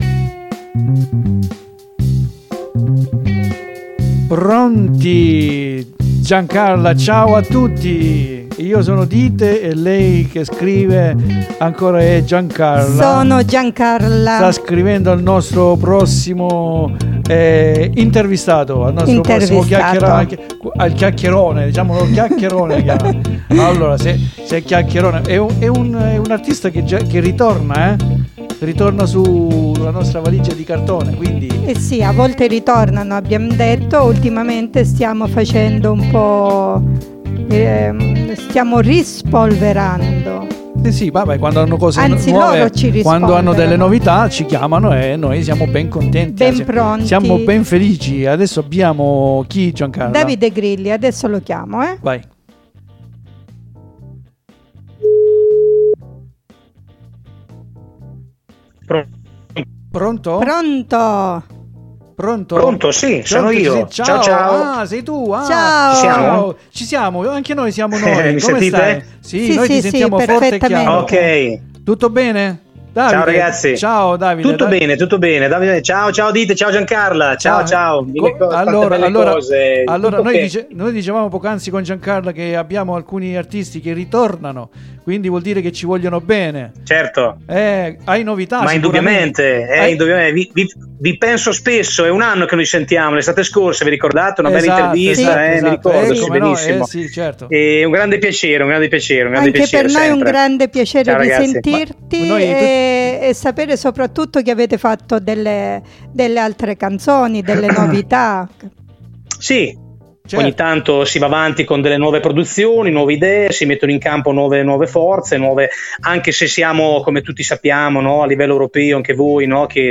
QUINDI VI AUGURO BUON ASCOLTO DELL'INTERVISTA, VI ALLEGO LINK YOUTUBE, DOVE CI SARANNO LE INDICAZIONI COME AIUTARE IL PROGETTO.